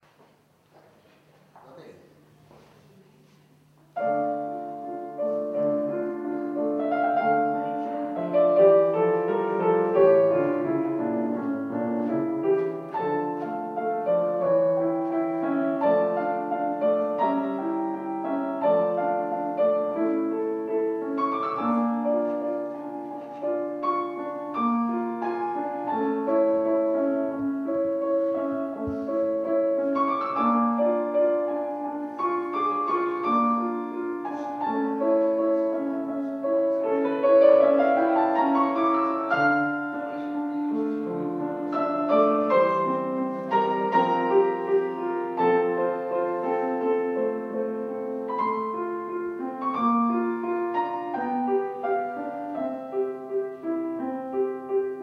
II OTTOBRE MUSICALE A PALAZZO VALPERGA, 17 ottobre 2009 - Concerto - Arie Italiane - C.W. GLUCK da Orfeo ed Euridice - Danza degli spiriti beati pianoforte solo